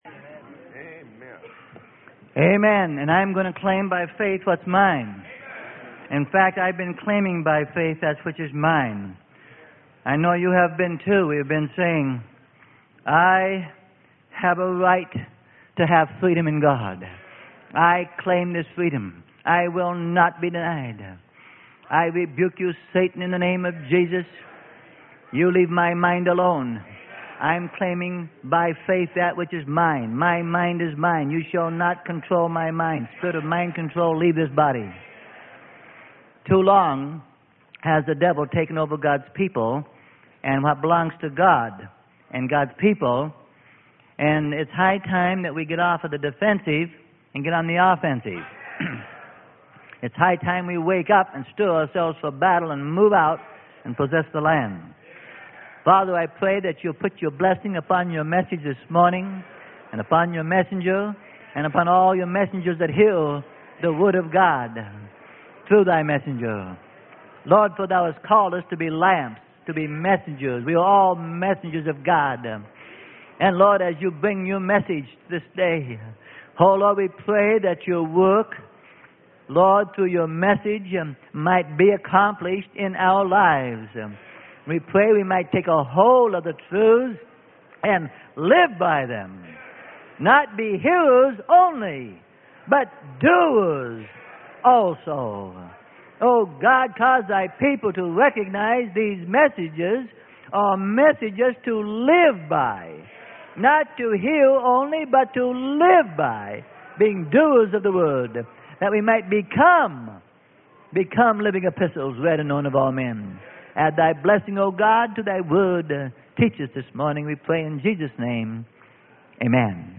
Sermon: LESSONS FROM HEROES OF FAITH.